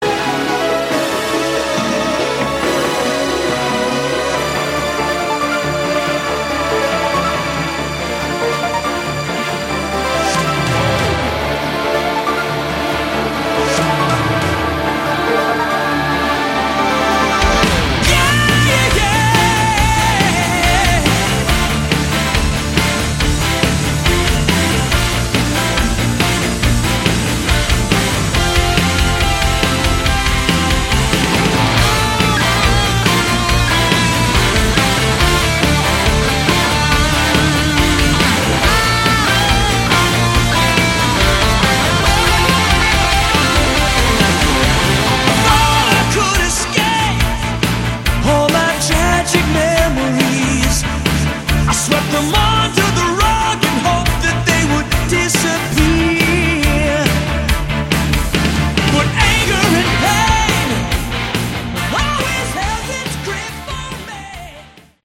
Category: Melodic Rock
rhythm & lead guitars, bass
keyboards
drums, keyboards